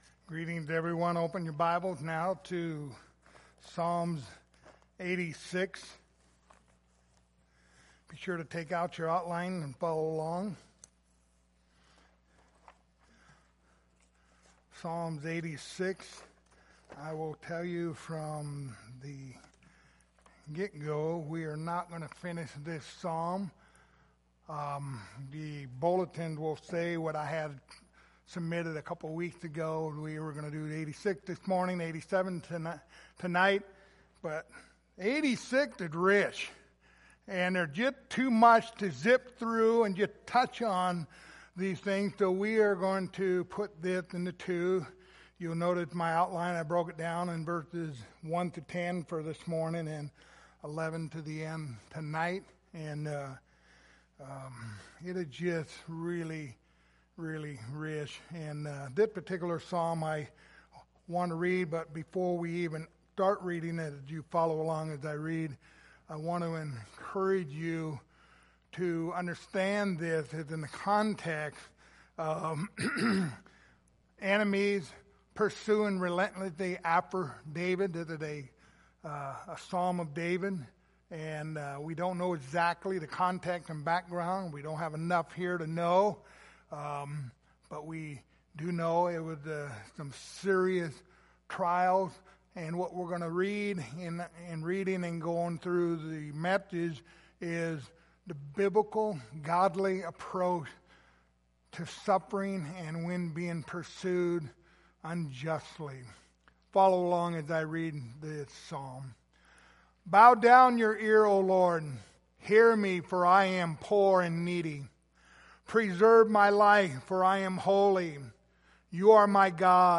The book of Psalms Passage: Psalms 86:1-10 Service Type: Sunday Morning Topics